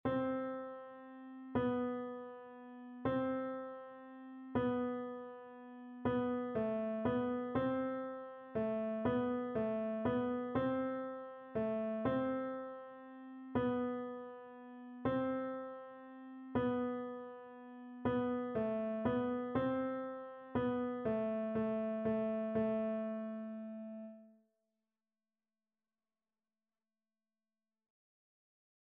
3/4 (View more 3/4 Music)
Beginners Level: Recommended for Beginners
Piano  (View more Beginners Piano Music)
Classical (View more Classical Piano Music)